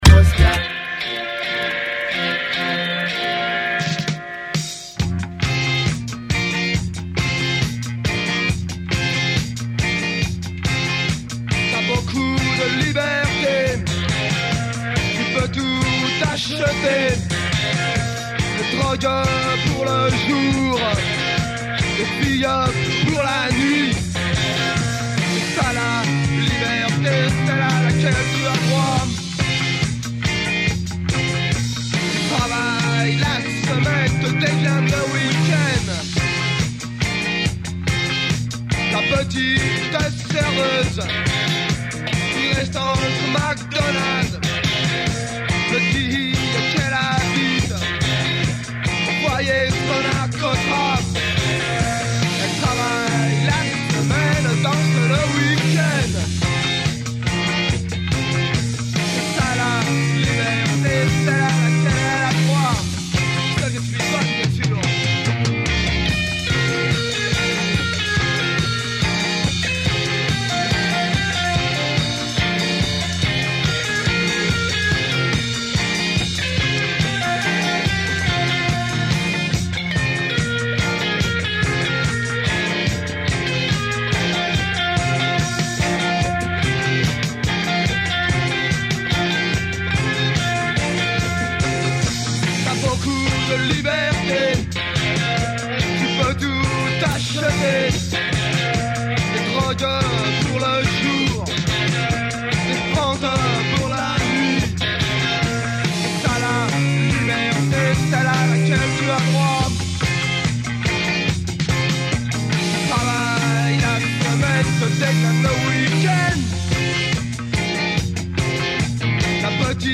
qui font du reggae